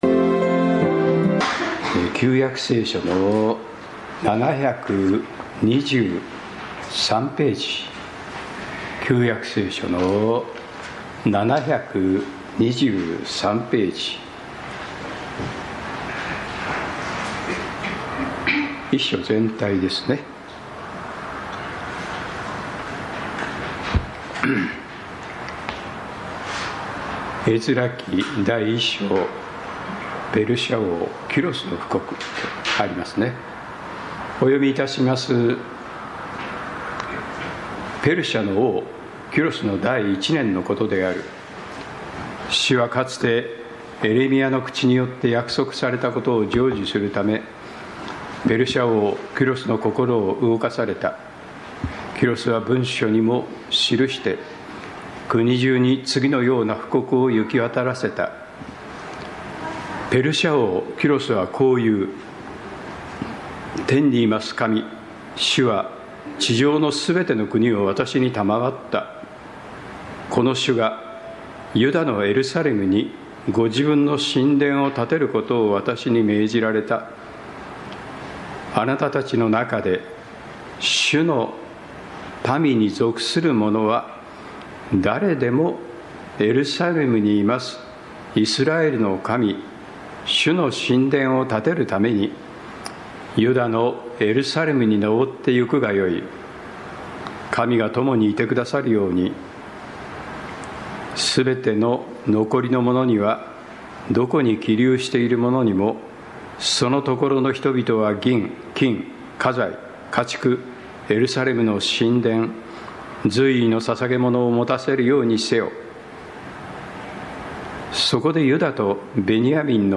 人の心を感動される神(録音に失敗しています。聖書朗読だけです。) 宇都宮教会 礼拝説教
人の心を感動される神(録音に失敗しています。聖書朗読だけです。)